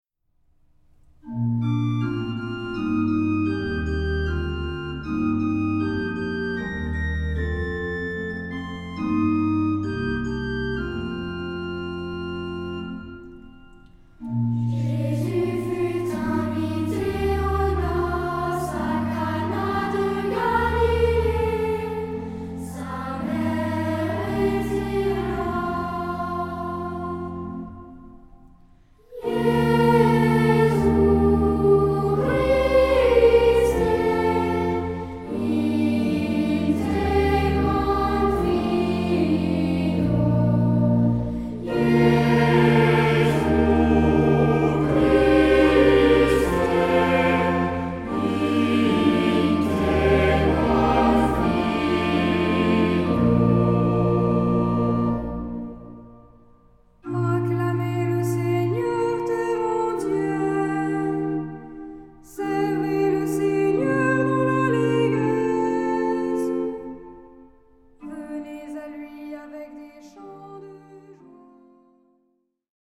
Genre-Style-Forme : Tropaire ; Psalmodie
Caractère de la pièce : recueilli
Type de choeur : SATB  (4 voix mixtes )
Instruments : Orgue (1)
Tonalité : si bémol majeur